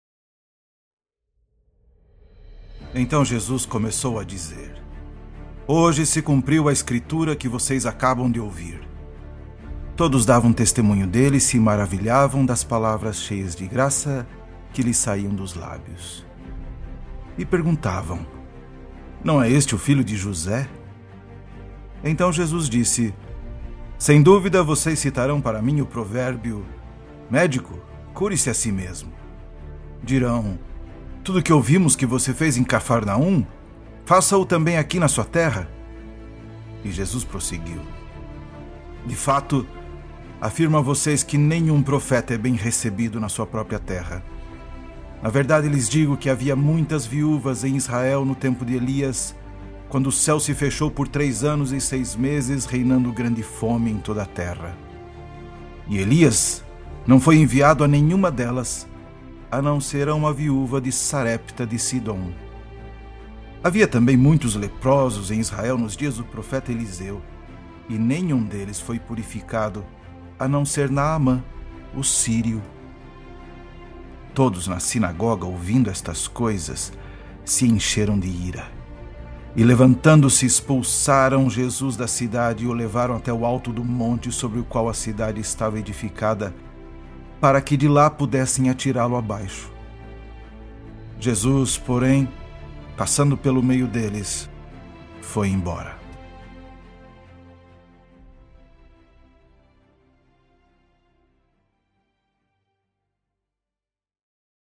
Leitura do Evangelho para o Quarto Domingo após Epifania, Ano C, Lucas 4.21-28 (NAA)